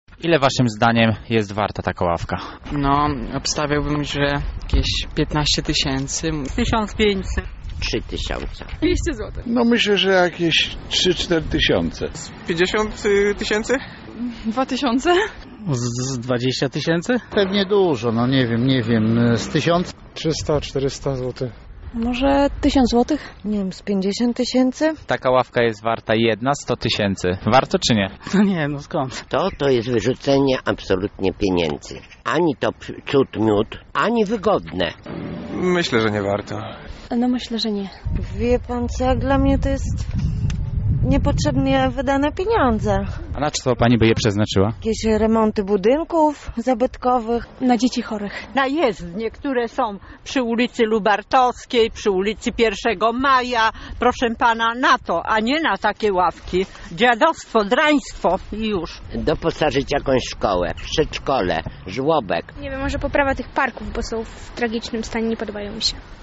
Zapytaliśmy mieszkańców Lublina na ile szacują koszt takiej ławki:
Sonda na temat parkletów
sonda.mp3